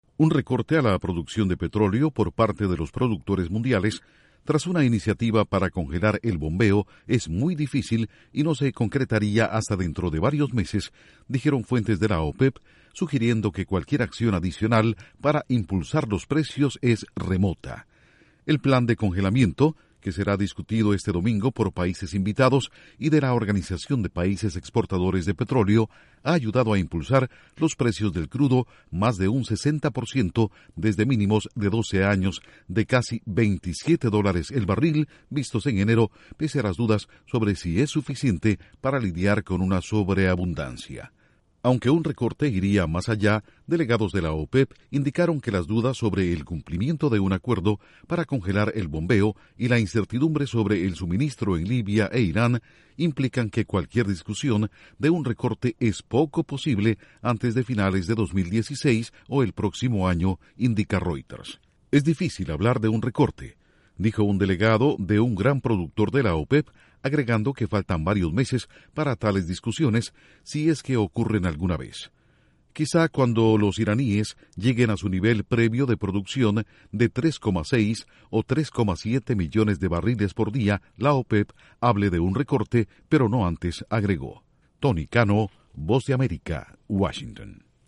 Un eventual recorte en la producción de petróleo sería muy difícil de lograr, y cualquier medida para impulsar los precios es remota, dicen expertos de la OPEP. Informa desde la Voz de América en Washington